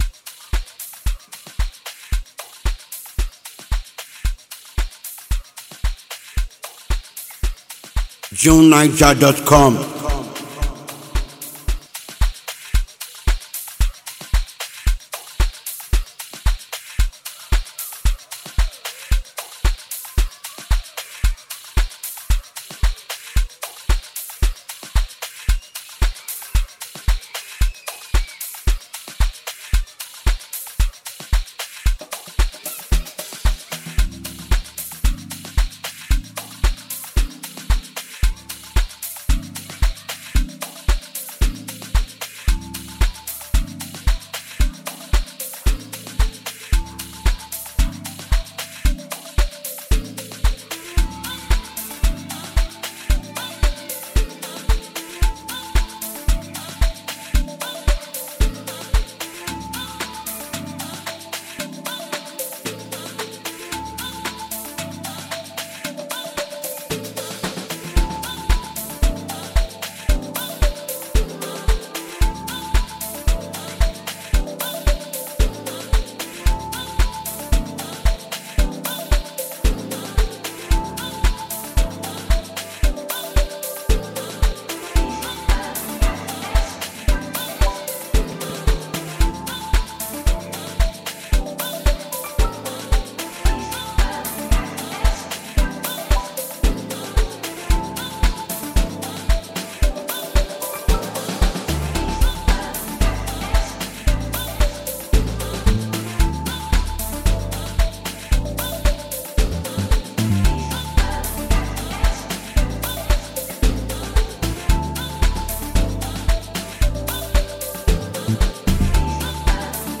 South African music producer
sends a welcome wave of optimism